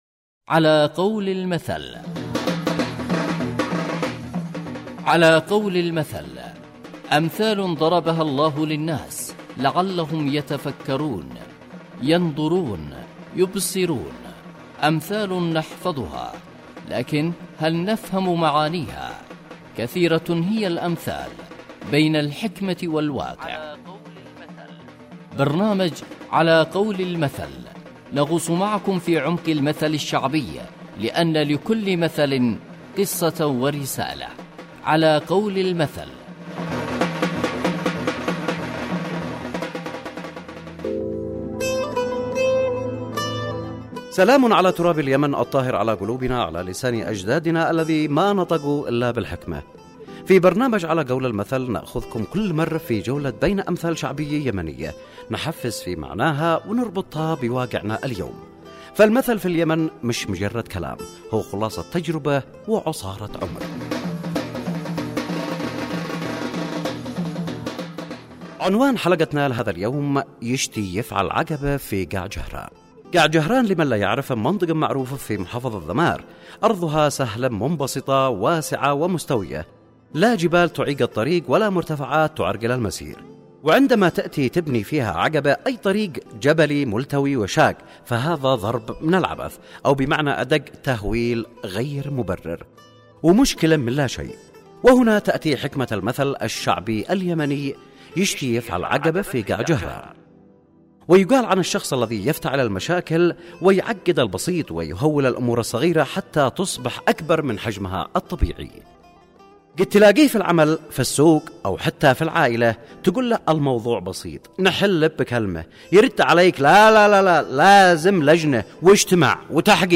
برنامج إذاعي يحكي عن معاني الامثال والحكمة منها كالمثل العسكري أو المثل المرتبط بأية قرآنية او المثل الشعبي . ويقدم تفسير للمثل والظروف التي أحاطت بالمثل وواقع المثل في حياتنا اليوم ويستهدف المجتمع.